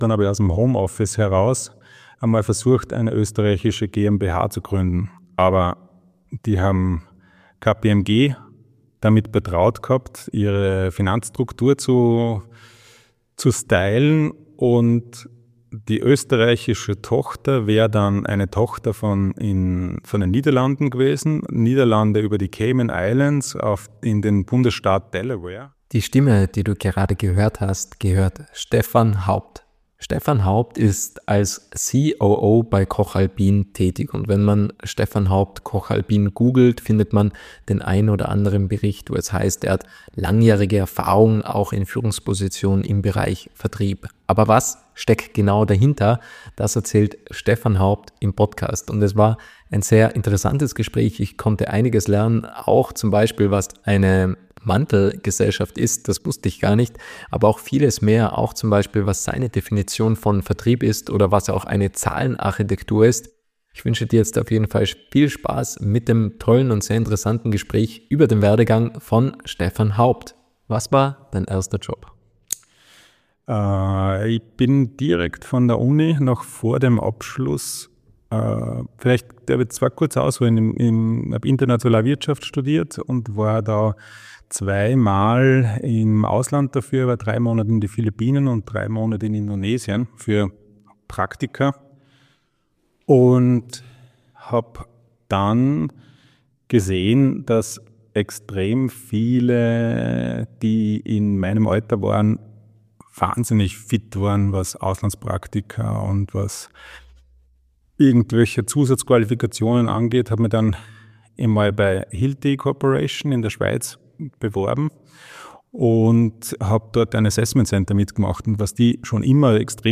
Es sind die kleinen Geschichten, die bei Menschen großes bewirken können. Das Podcast- Format little talks vermittelt in 30-minütigen Interviews spannende Impulse, welche bewegen, zum Nachdenken anregen und Identifikationspotenziale schaffen.